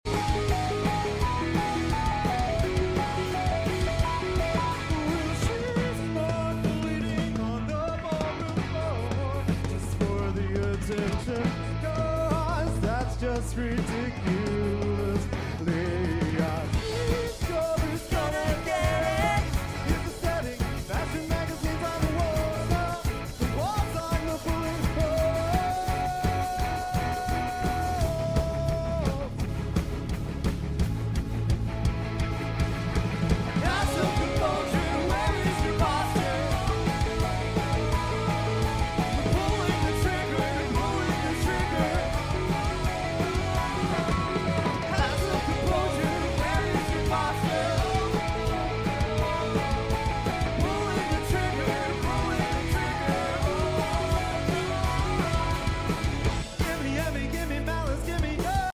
heres our cover